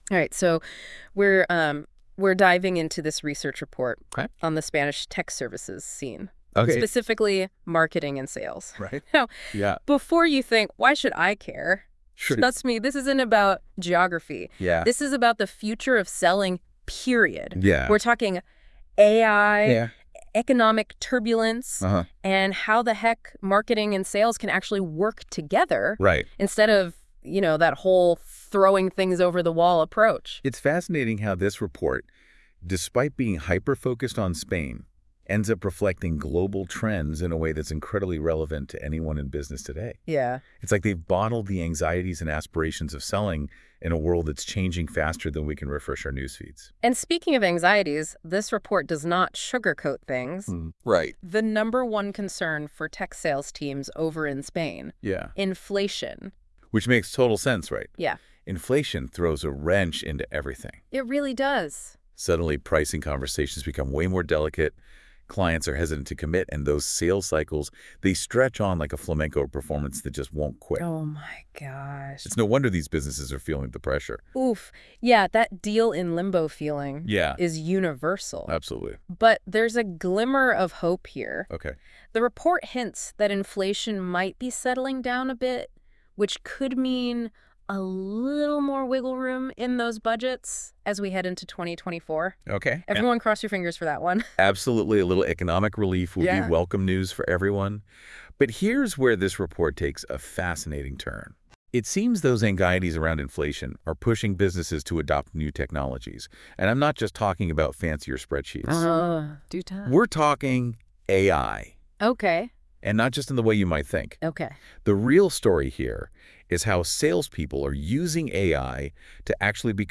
entrevista de podcast a dos voces.